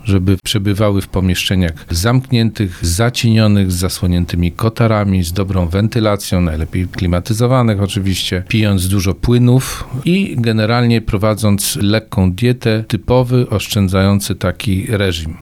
O tym, jak osoby w podeszłym wieku mogą uchronić się przed skutkami tropikalnych temperatur mówi specjalista medycyny rodzinnej